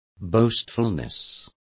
Complete with pronunciation of the translation of boastfulness.